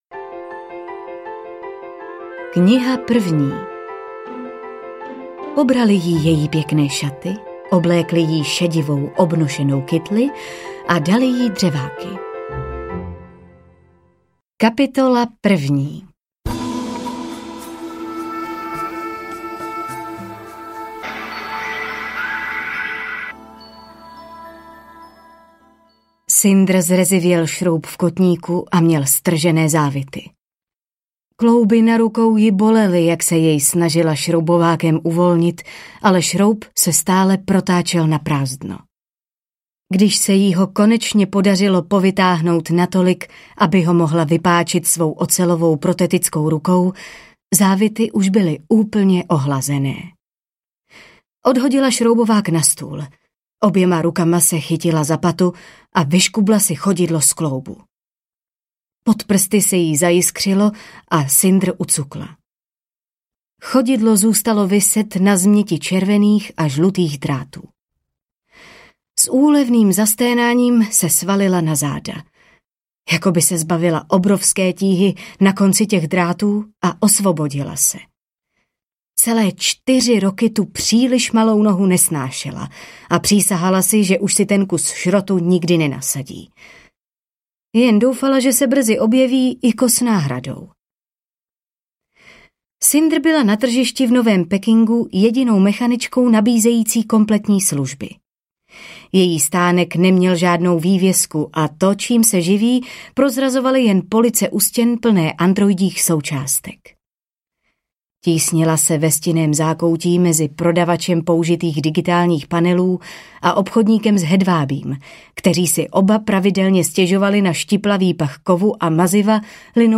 Cinder - Měsíční kroniky audiokniha
Ukázka z knihy